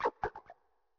Cri de Nigirigon dans sa forme Raide dans Pokémon Écarlate et Violet.
Cri_0978_Raide_EV.ogg